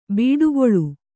♪ bīḍugoḷu